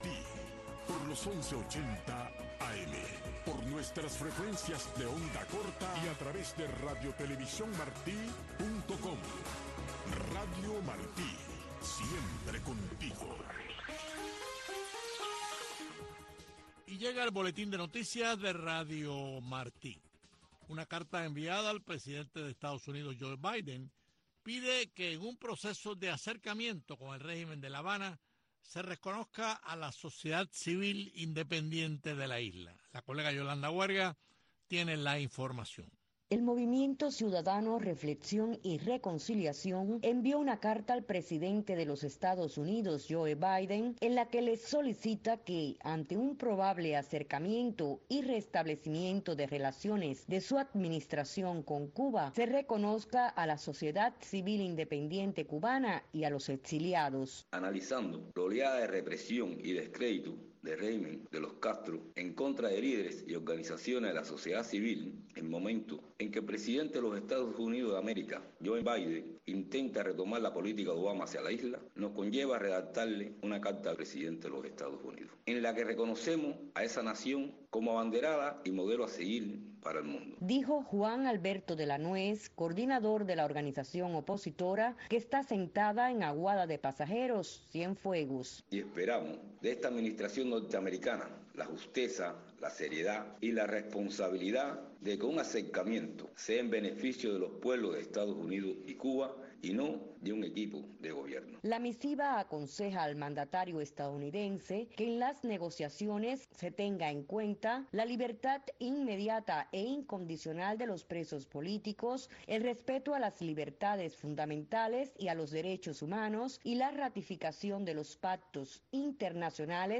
Entrevistas e informaciones con las voces de los protagonistas desde Cuba.